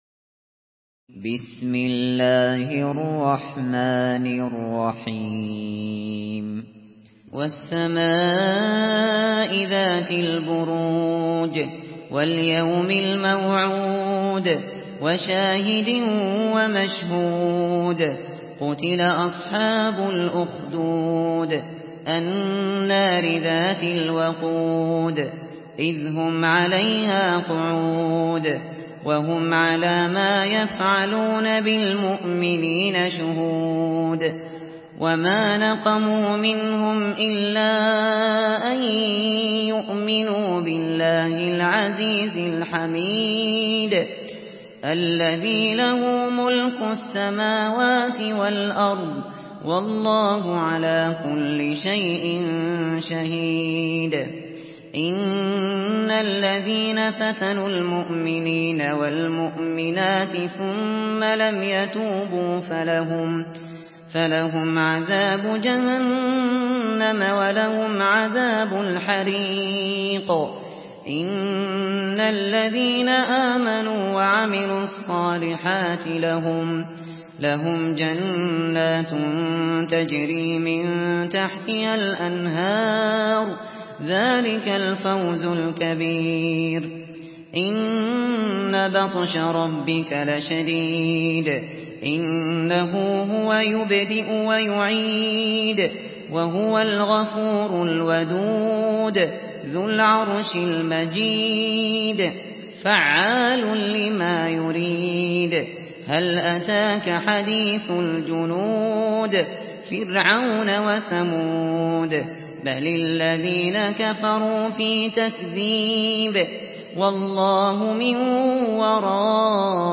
Surah البروج MP3 by أبو بكر الشاطري in حفص عن عاصم narration.